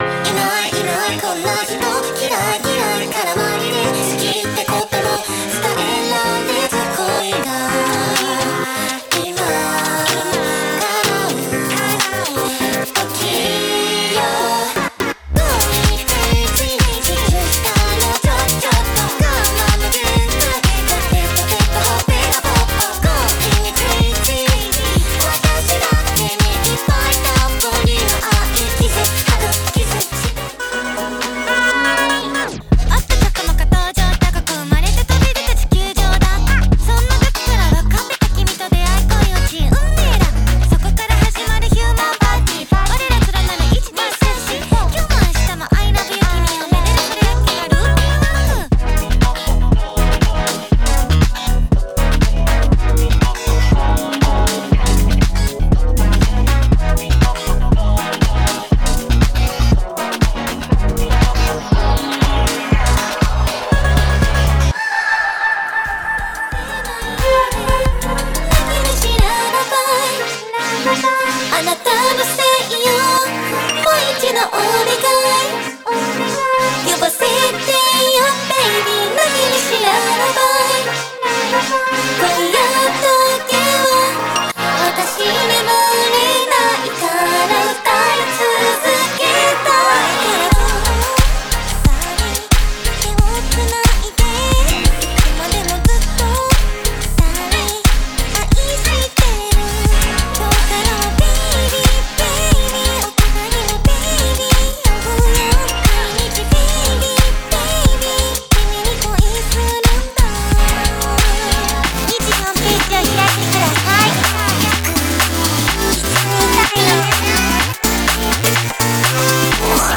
融合了电子注入的J-pop
毫无疑问，这款套件中的超级Kawaii人声是这款包装独特的原因。我们是在日本录制的真实唱片